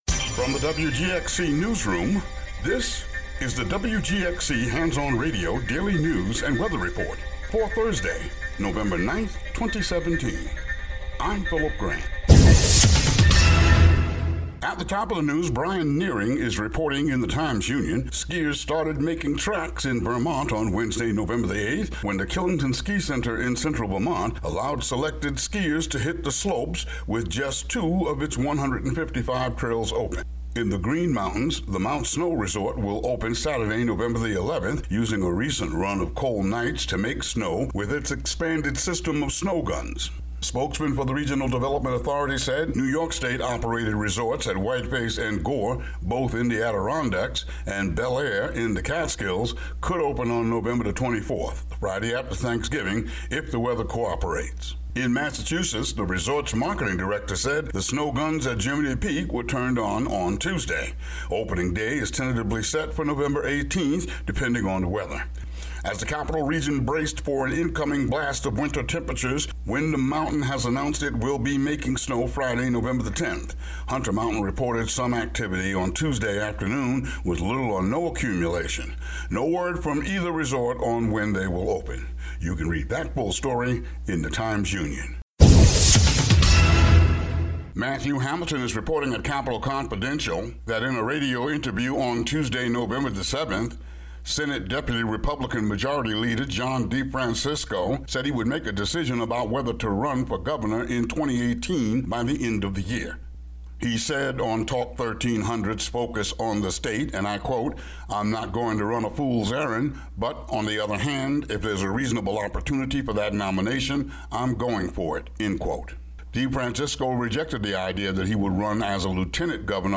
Local news update, and then radio news about radio...